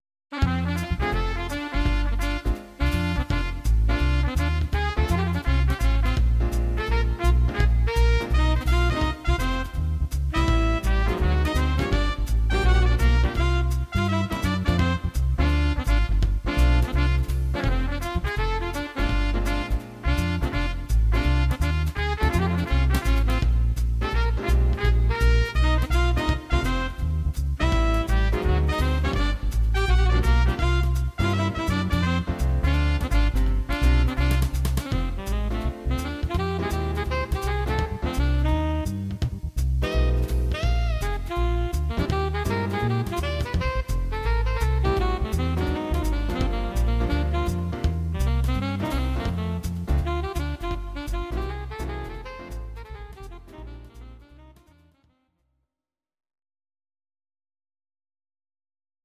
Arr. Jazz Quartet